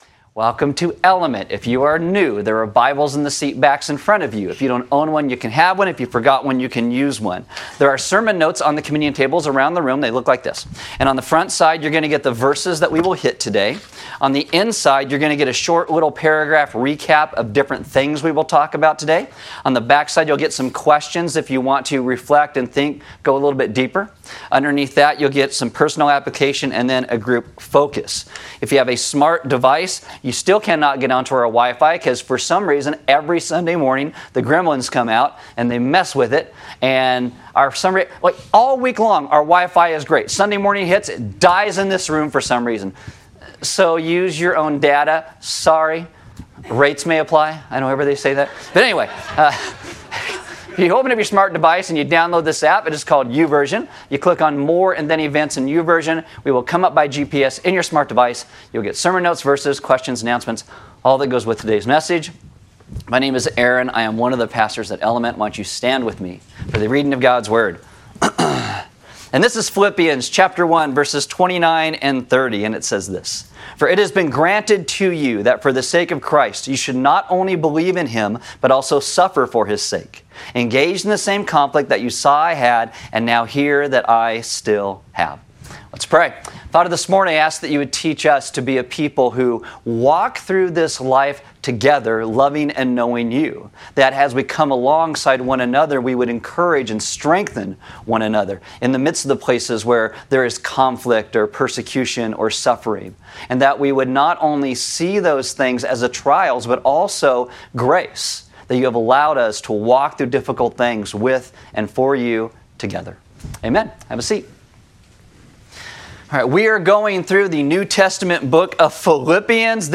Service Audio Paul starts the first part of Philippians by talking about his emotions and struggles; he will then spin it back to talk about the Philippians’ struggle (which is actually the same struggle).